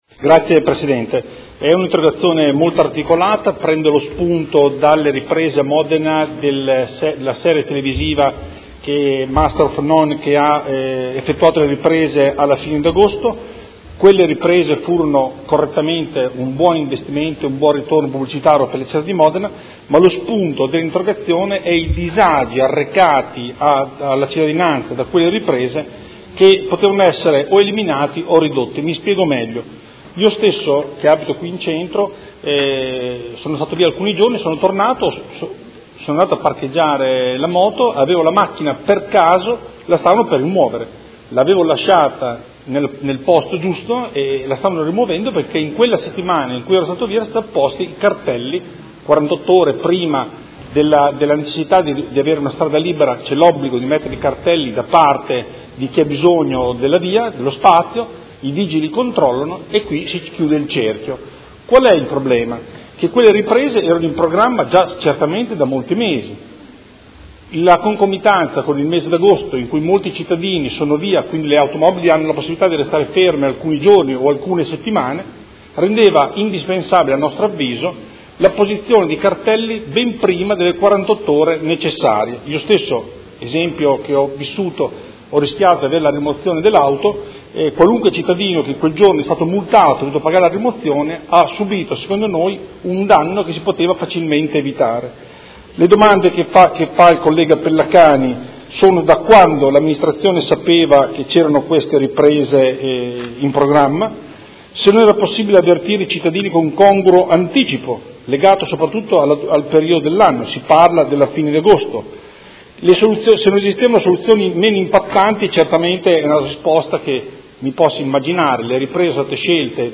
Seduta del 6 ottobre. Interrogazione dei Consiglieri Pellacani e Galli (F.I.) avente per oggetto: Rimozione delle automobili in Centro Storico per le riprese di Master of None: una grave ingiustizia nei confronti degli ignari cittadini